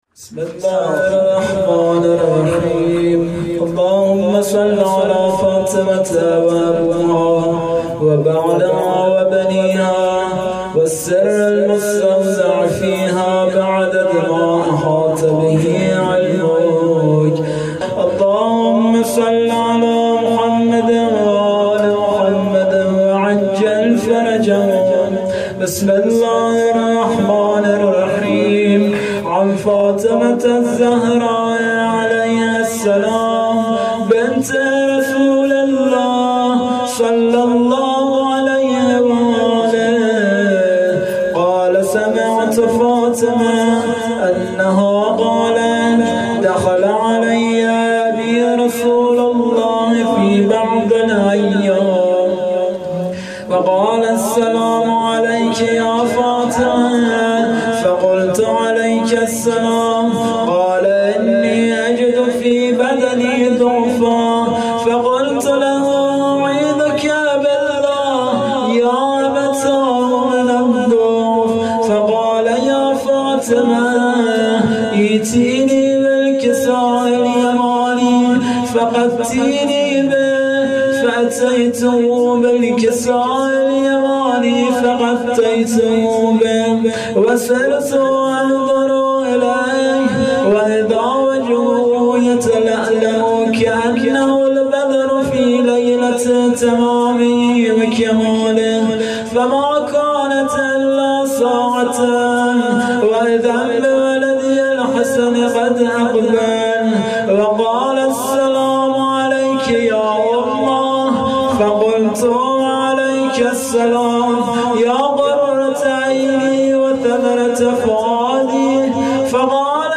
هیئت رایت الهدی کمالشهر
مداحی فاطمیه